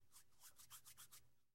Звуки рукопожатия
Звук рукопожатия при встрече